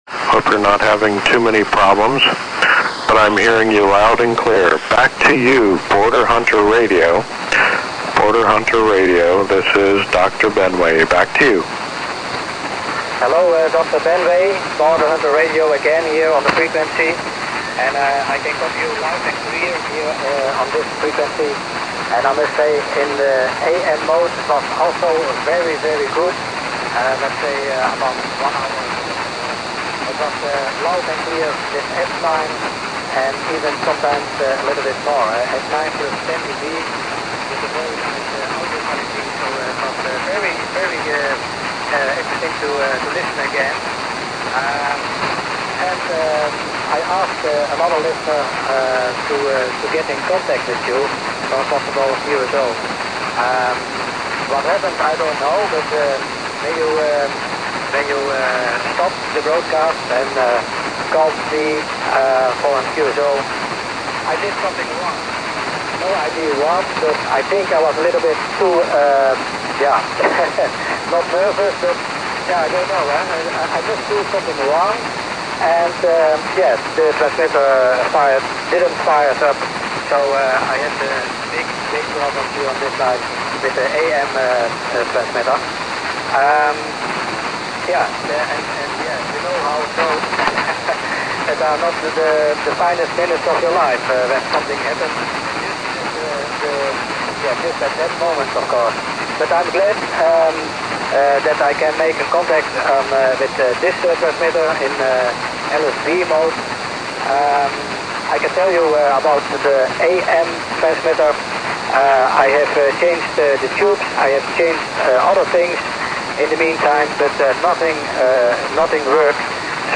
Location: Conception Bay South, Newfoundland, Canada
Receiver: JRC NRD-525
Antenna: 75 m long wire, 30 m from house
undercover-borderhunter-qso.mp3